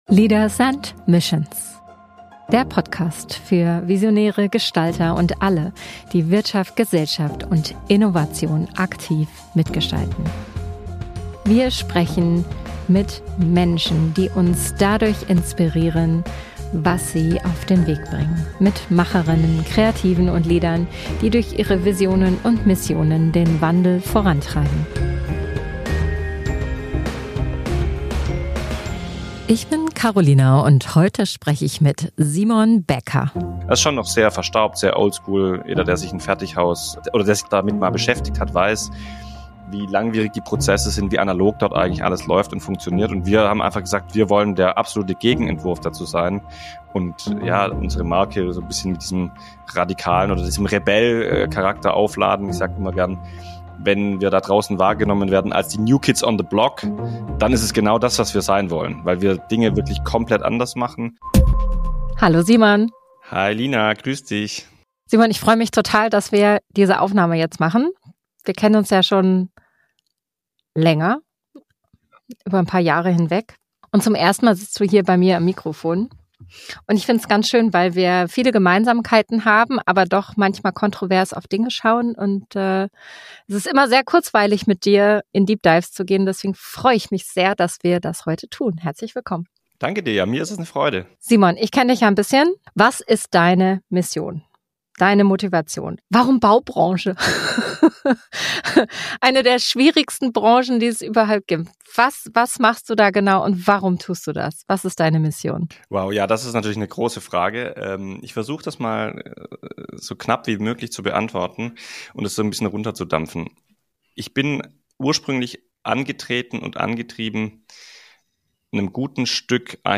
Ein Gespräch über unternehmerischen Mut, systemisches Denken und den Preis, den echte Innovation manchmal fordert.